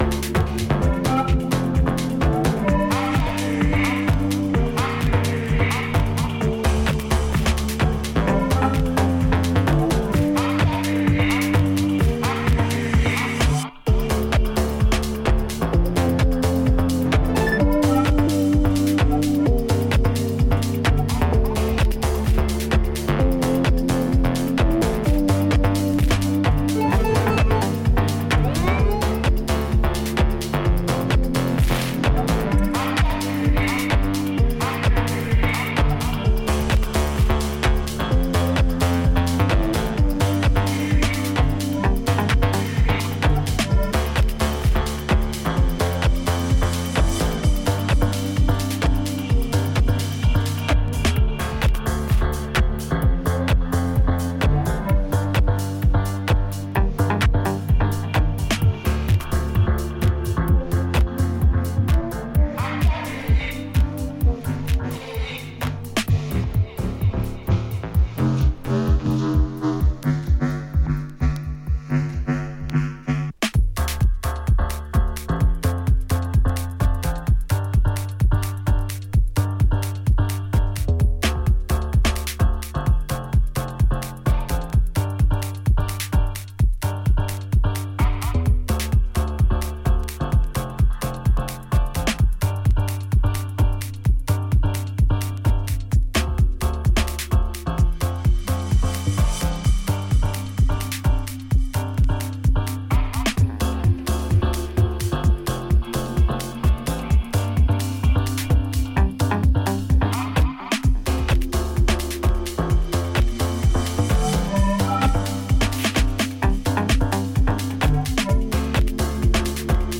ノスタルジックなコードが印象的なミニマル・ハウス